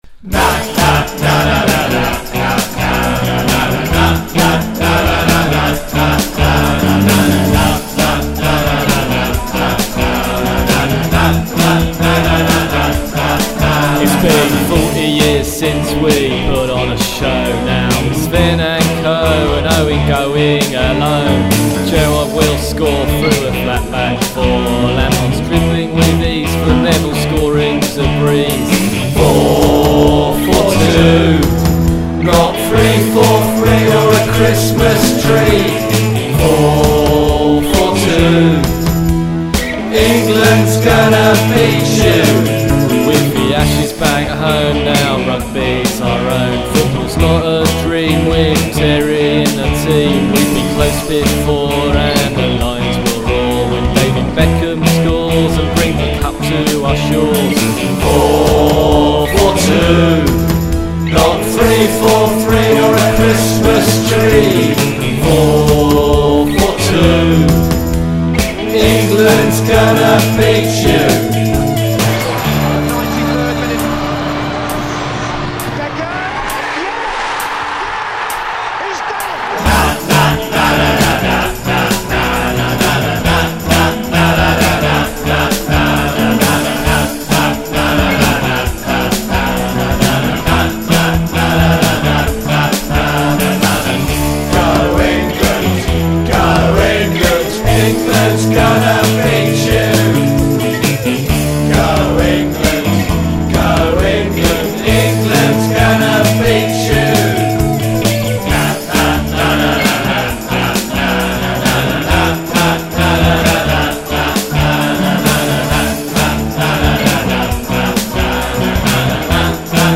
442singalong.mp3